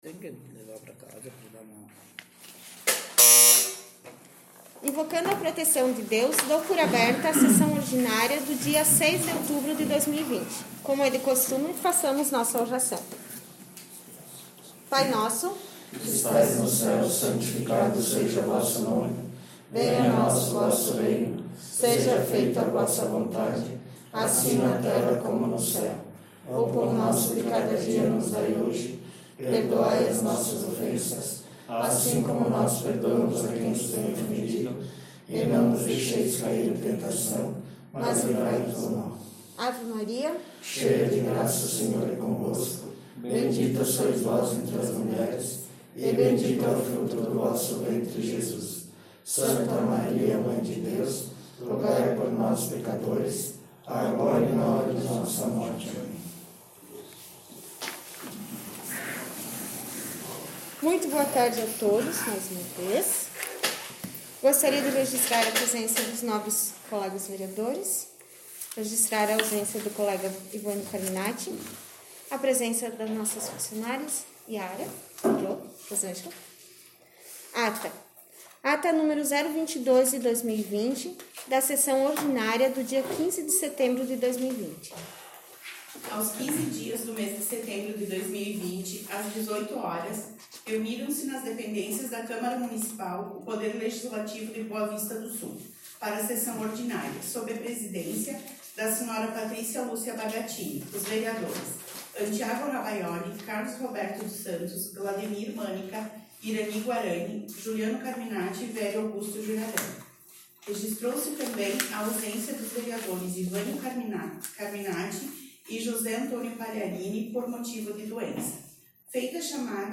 Sessão Ordinária 06/10/2020 — Câmara Municipal de Boa Vista do Sul